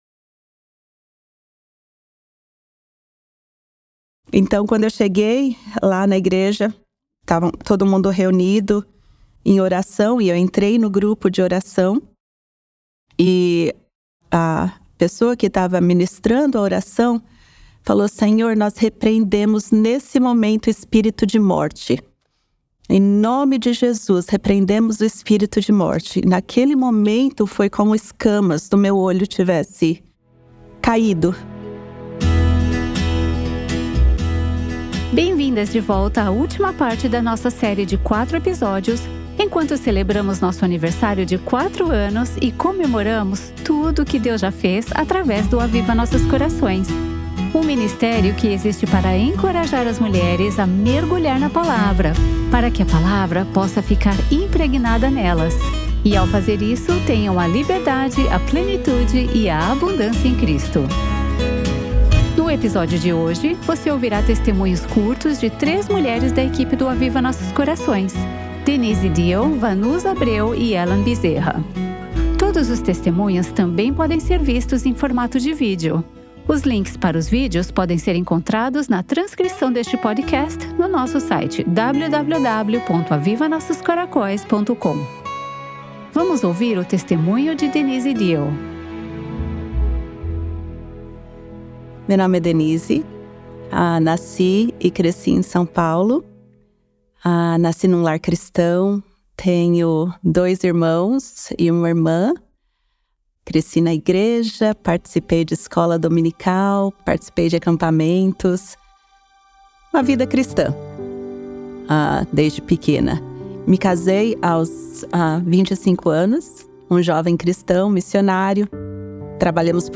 Hoje, você ouvirá o testemunho de três mulheres da equipe Aviva Nossos Corações sobre como Deus usou o estudo Mulher Verdadeira para transformar as suas vidas.